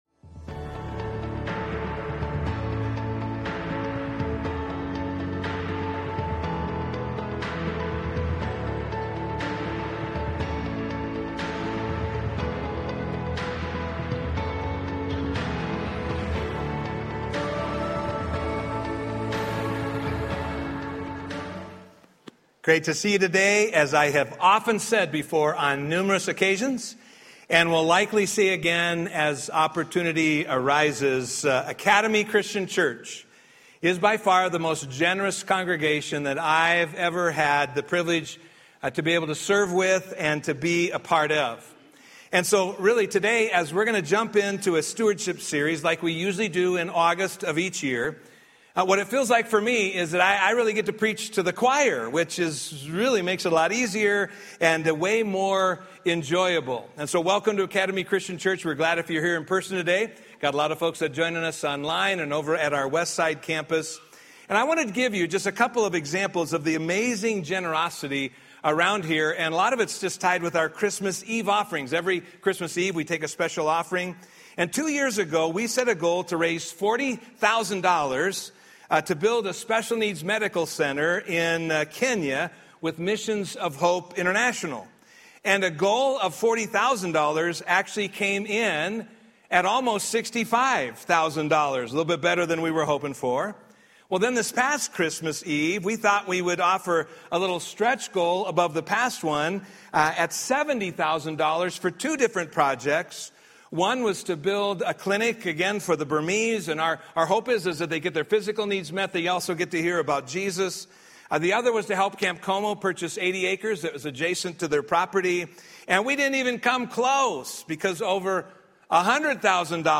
Message: “One Child” from Guest Speaker